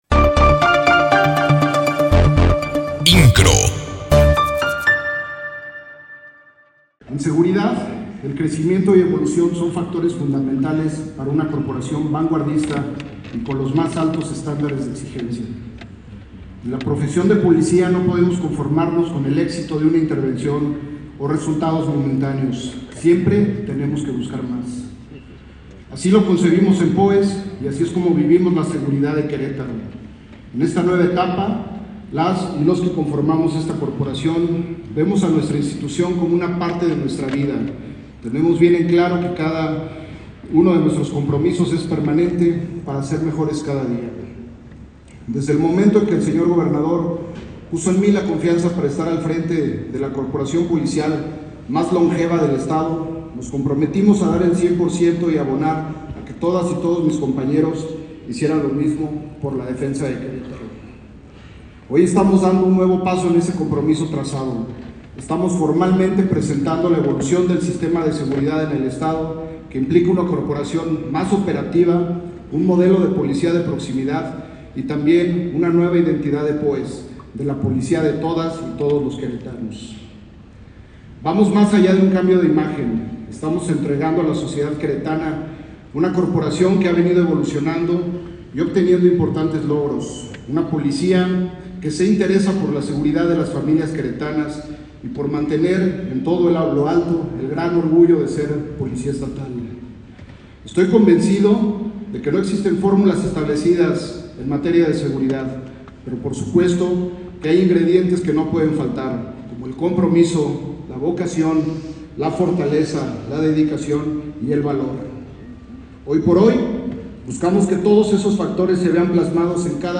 El evento se realiza en el Teatro Metropolitano y es encabezado por el Gobernador Mauricio Kuri Gonzalez y con la presencia de alcaldes, diputados y personalidades de la política, el medio empresarial y los medios de comunicación.
El Secretario de Seguridad Ciudadana Iovan Pérez hace uso de la palabra en primer término.